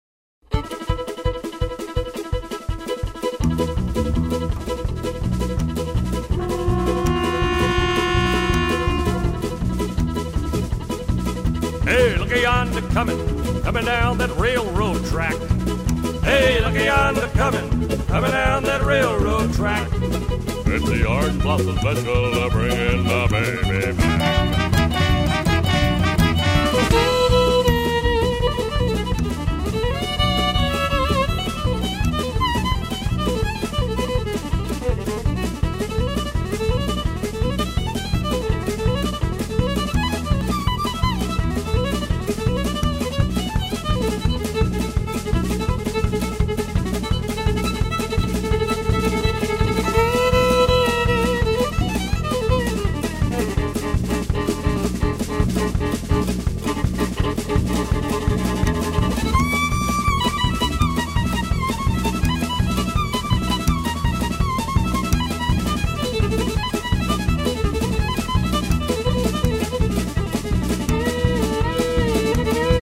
Dixie Jazz / Compilation